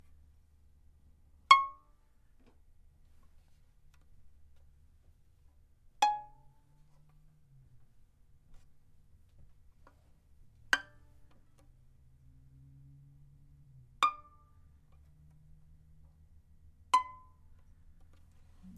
小提琴声音 " 小提琴D弦旋律
描述：D弦上小提琴的小旋律，到达A音符。
标签： 非电子效应 弦乐 小提琴 搔抓
声道立体声